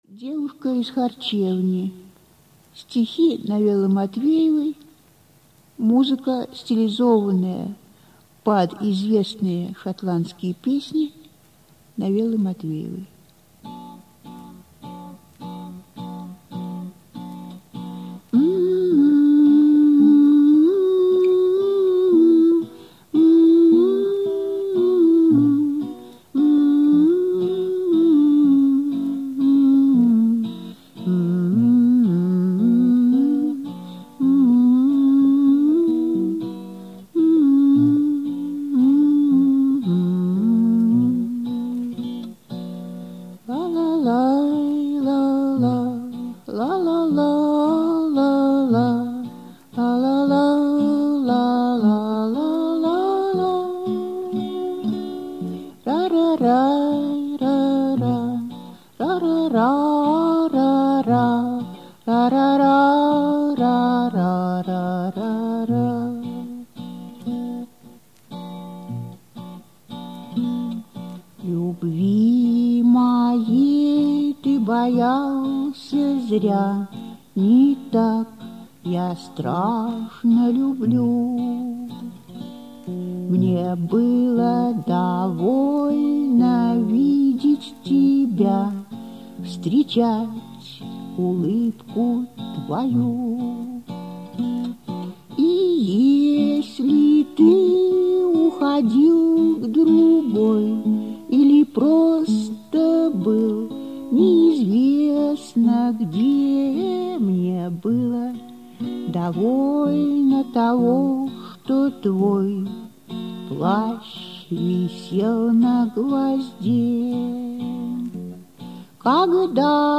авторское исполнение